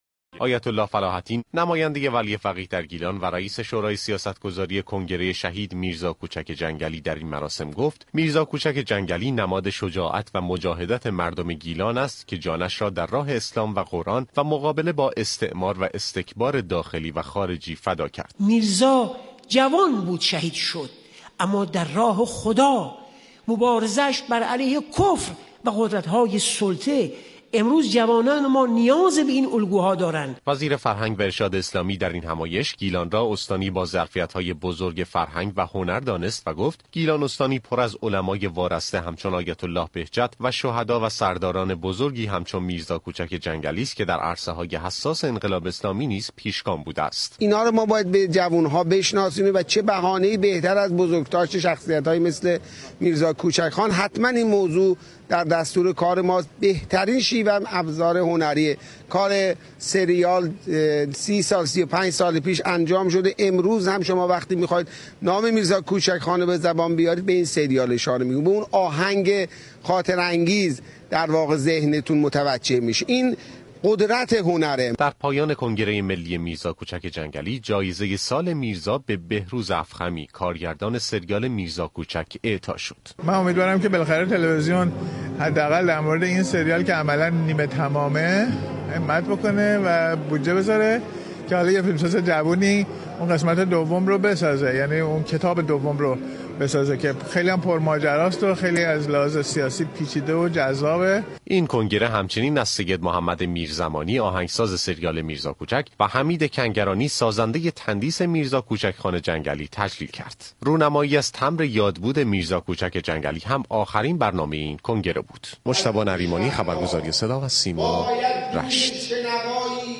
اجلاسیه پایانی كنگره بین المللی میرزا كوچك خان جنگلی در تالار مركزی رشت